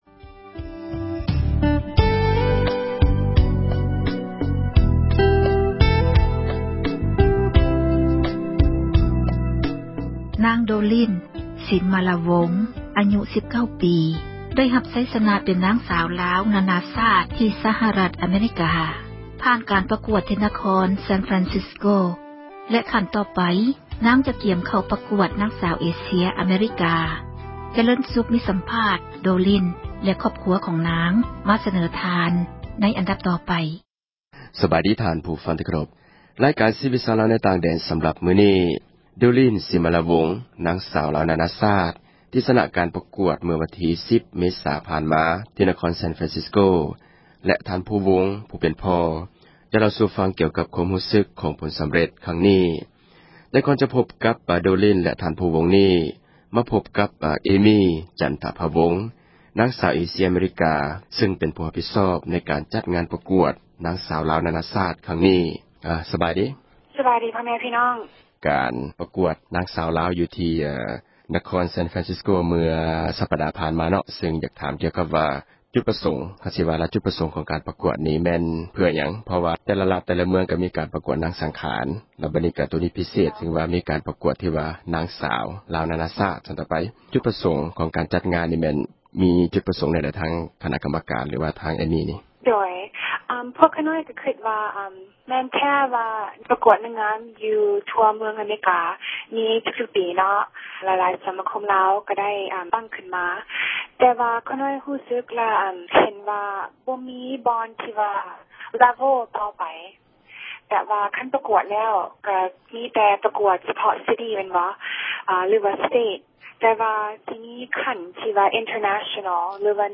ສັມພາດນາງສາວ